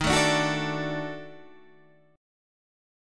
D# Minor Major 7th Flat 5th